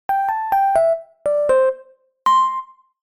알림음(효과음) + 벨소리
알림음 8_따라라란따라7-귀여운.mp3